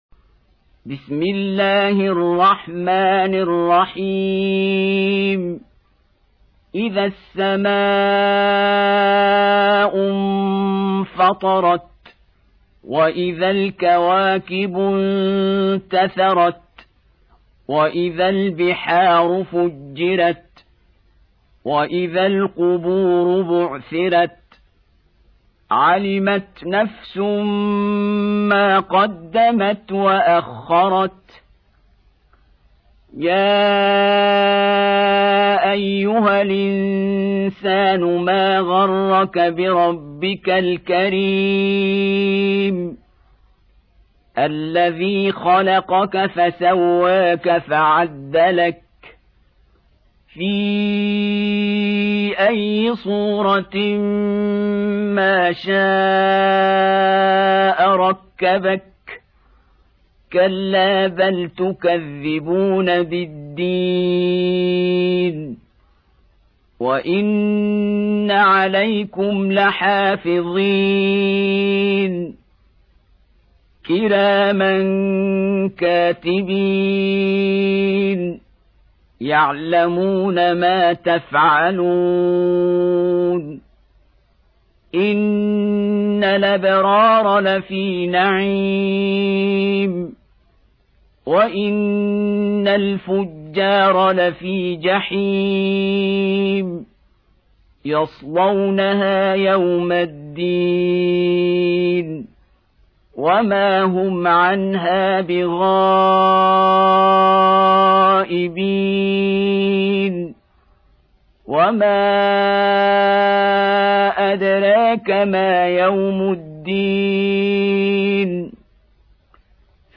82. Surah Al-Infit�r سورة الإنفطار Audio Quran Tarteel Recitation
Surah Repeating تكرار السورة Download Surah حمّل السورة Reciting Murattalah Audio for 82. Surah Al-Infit�r سورة الإنفطار N.B *Surah Includes Al-Basmalah Reciters Sequents تتابع التلاوات Reciters Repeats تكرار التلاوات